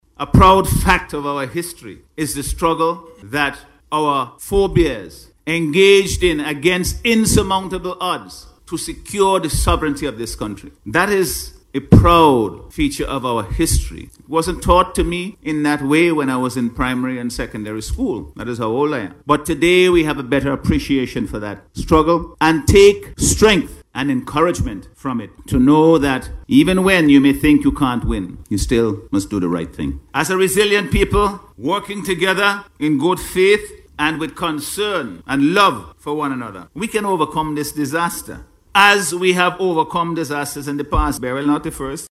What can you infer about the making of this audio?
He made this statement while contributing to the debate on the 2025 Budget this morning.